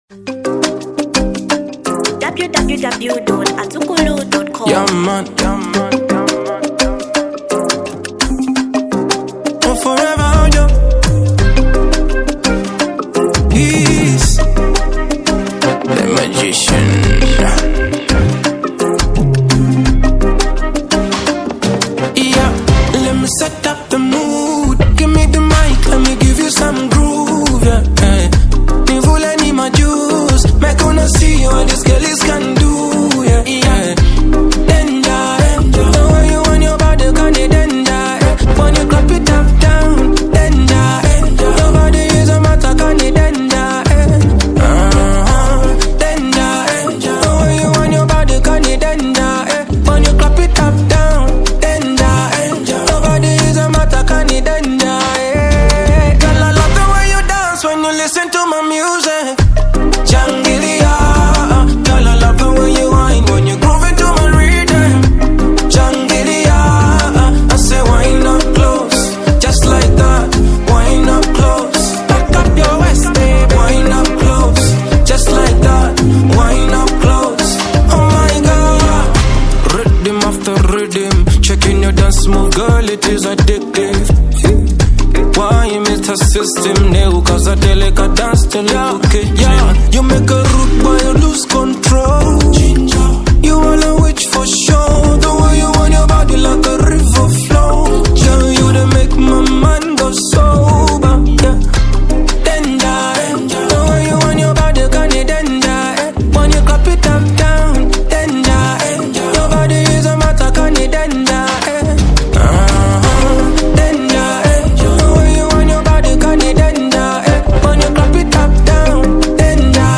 Genre Reggae & Dancehall